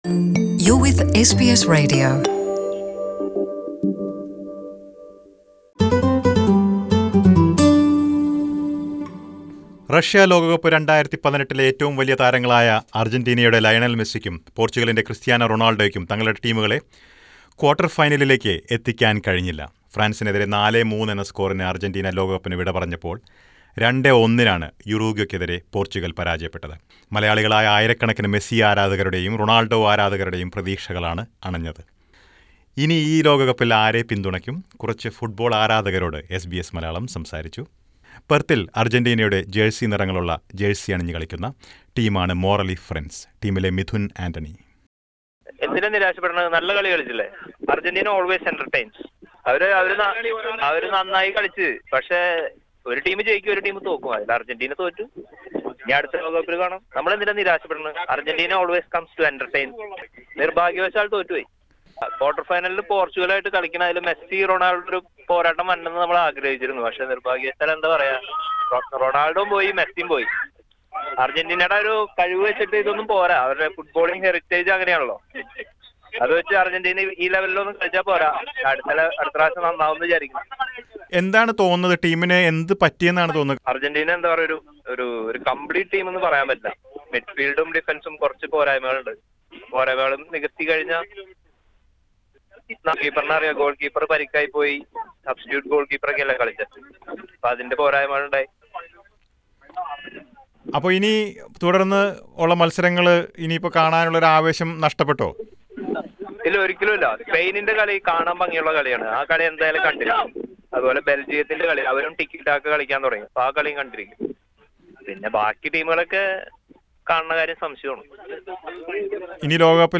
Listen to the opinions of a few Australian Malayalees.